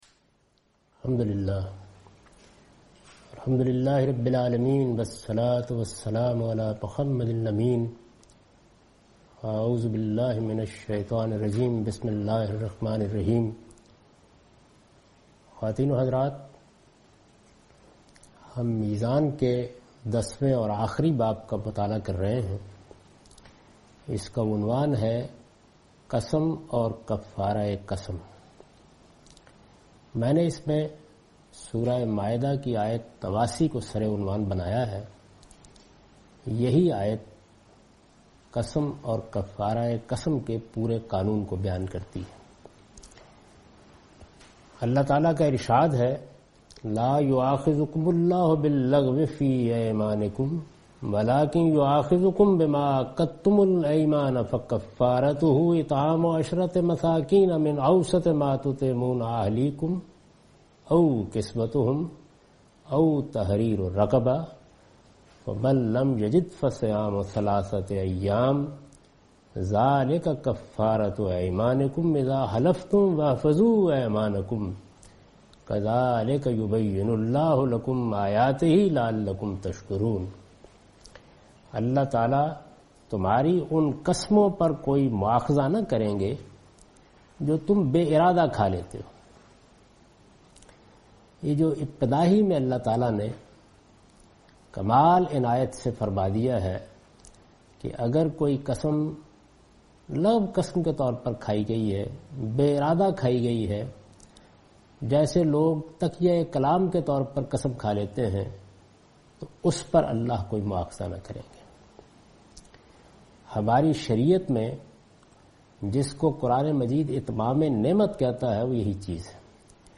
A comprehensive course taught by Javed Ahmed Ghamidi on his book Meezan. In this lecture he will discuss Oaths and their Atonement. He explains in great detail the place of Oaths and Atonement in Islamic Shari’ah.